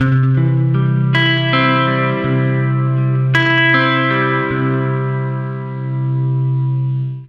80MAJARP C-R.wav